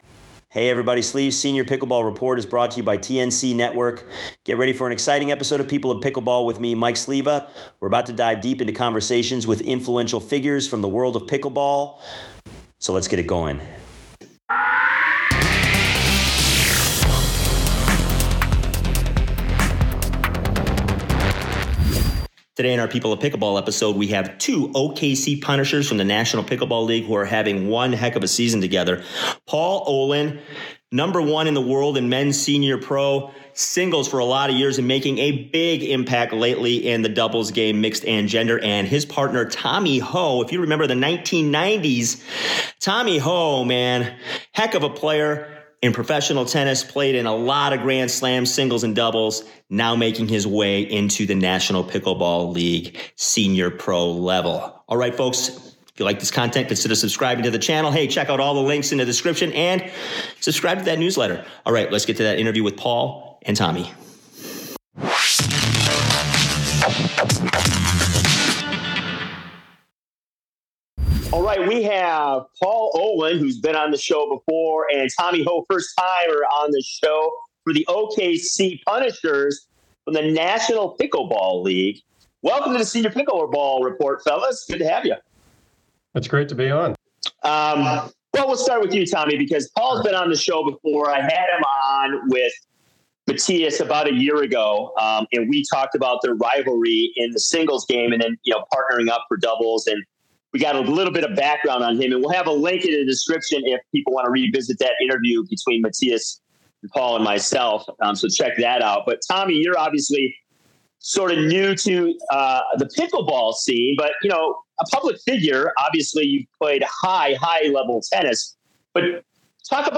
Tune in every week for engaging conversations, expert interviews, and the latest news in the pickleball world.